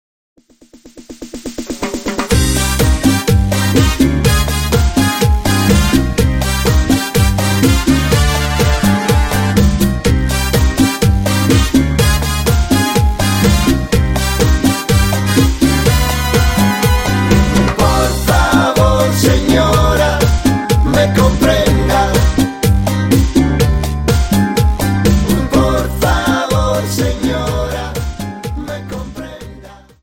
Dance: Cha Cha 31 Song